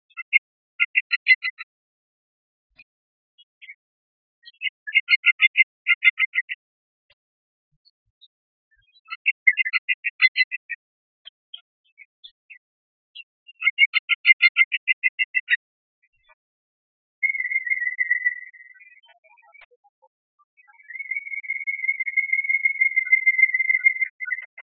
En cliquant ici vous entendrez le chant du Pic vert.
Le Pic vert